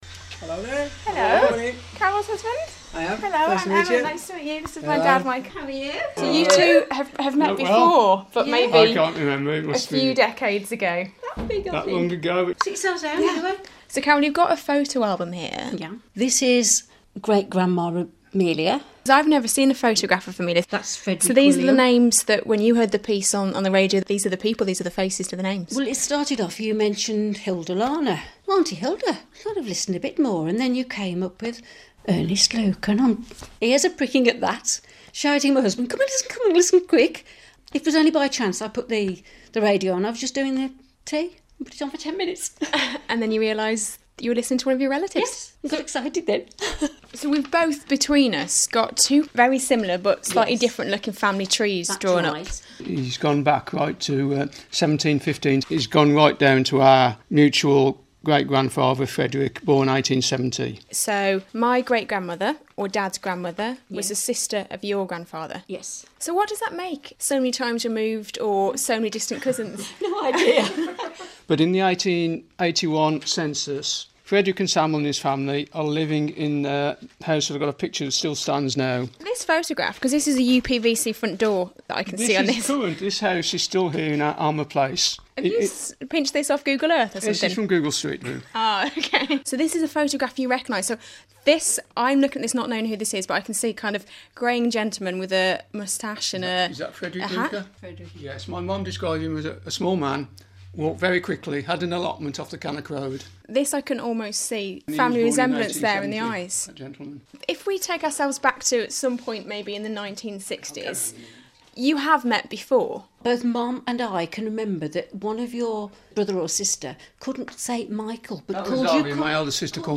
(Broadcast on BBC Stoke & BBC WM, July 2014)